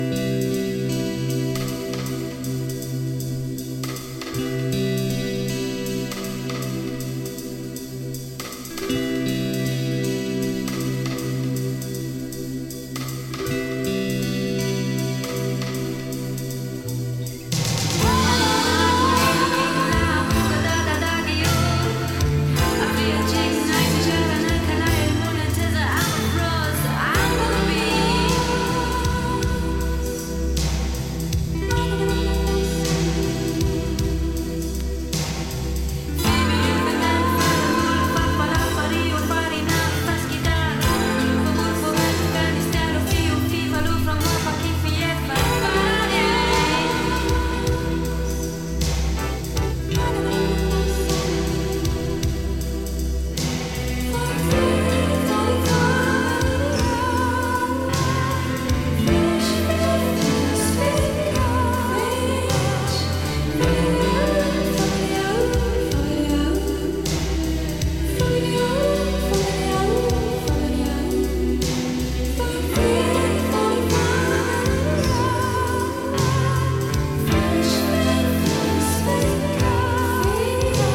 耽美的な世界観が素晴らしいドリームポップ傑作！